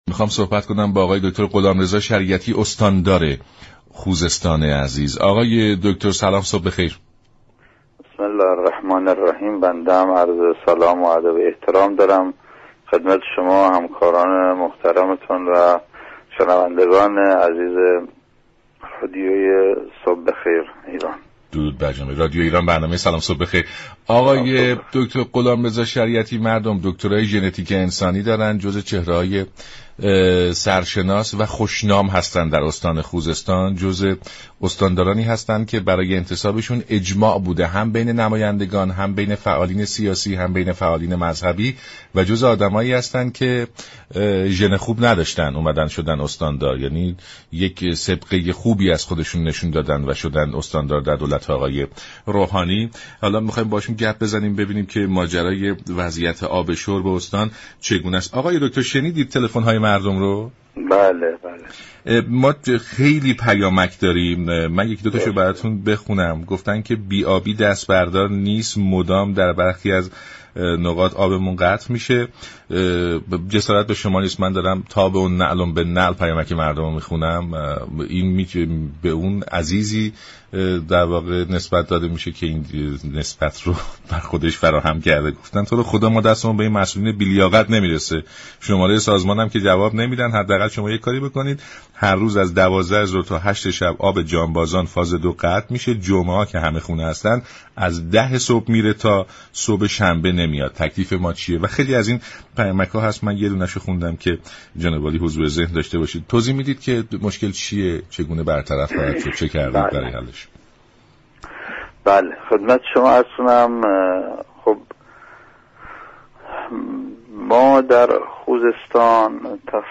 استاندار استان خوزستان در گفت و گو با رادیو ایران گفت: